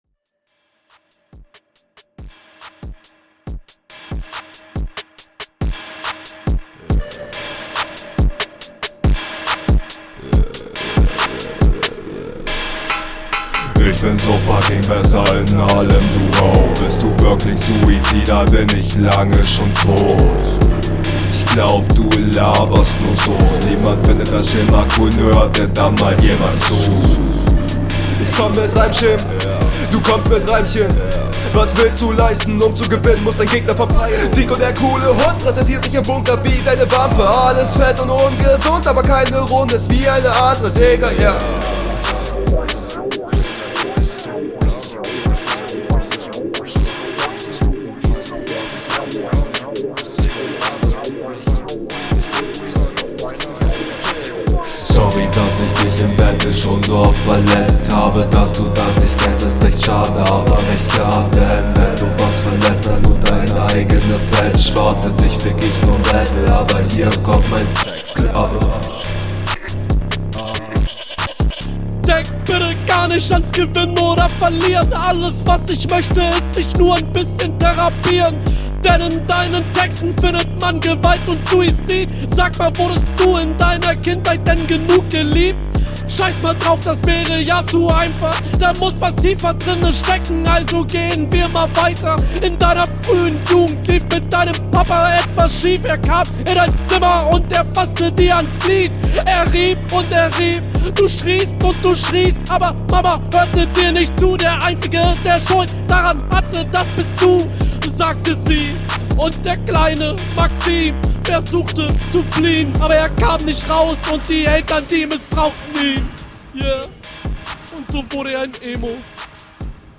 yeah nicer Beat.